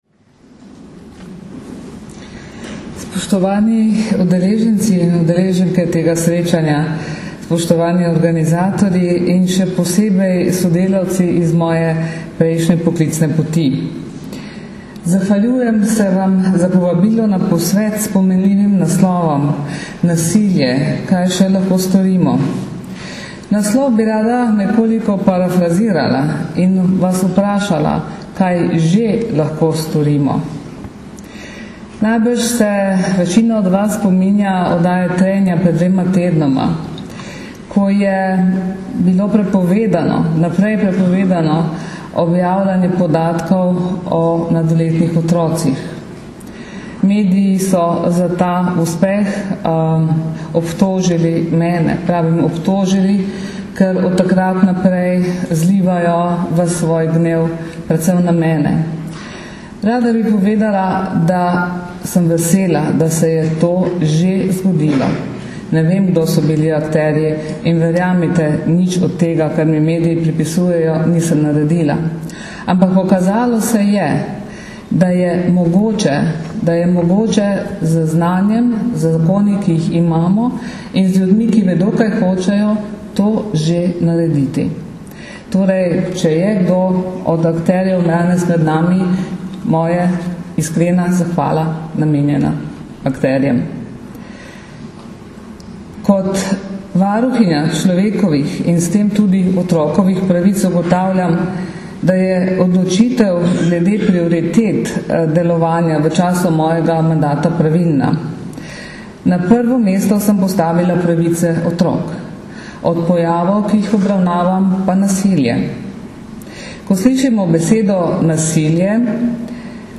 V Rogaški Slatini poteka danes in jutri, 3. in 4. 4. 2008, posvet z naslovom Nasilje, kaj še lahko storimo, ki ga vsako leto organizirata Uprava kriminalistične policije na Generalni policijski upravi in Društvo državnih tožilcev Slovenije.
Zvočni posnetek izjave varuhinje človekovih pravic dr. Zdenke Čebašek Travnik (mp3)